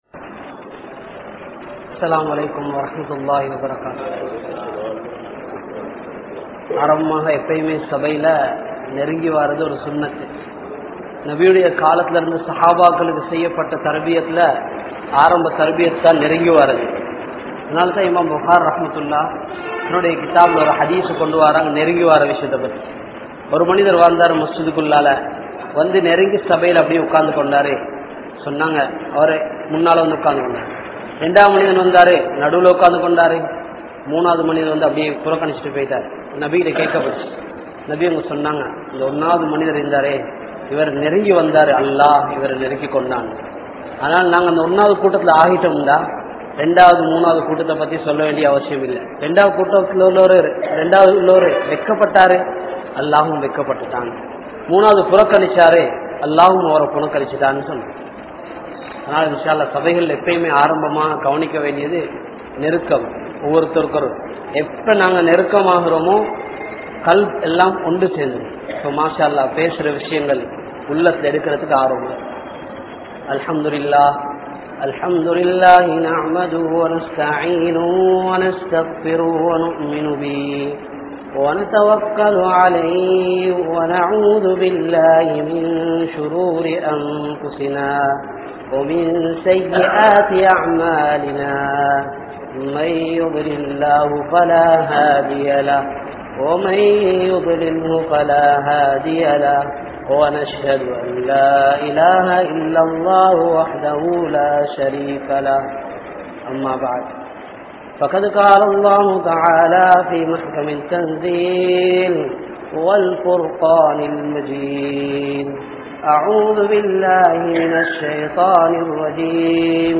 Allah`vin Sakthi (அல்லாஹ்வின் சக்தி) | Audio Bayans | All Ceylon Muslim Youth Community | Addalaichenai